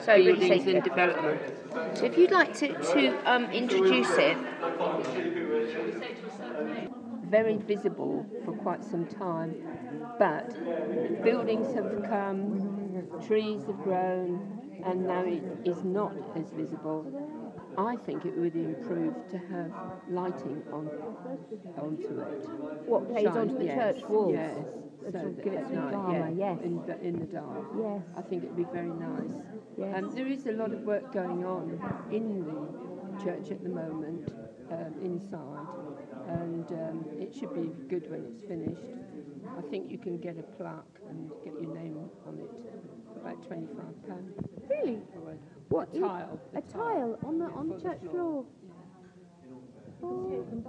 The recording itself starts in the middle of the story - sorry still fighting with balancing interviewing skills and the technology, but well worth a listen to.